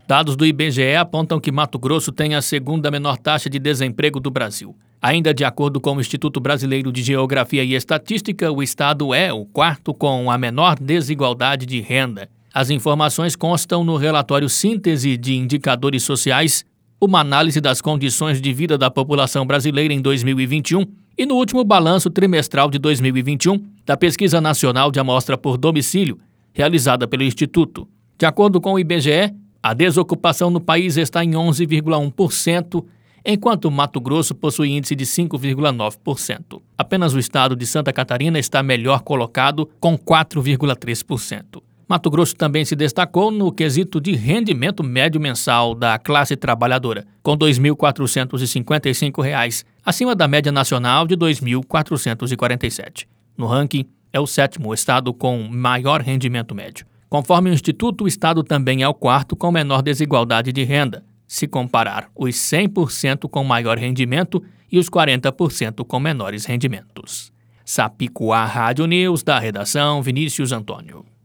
Boletins de MT 03 mar, 2022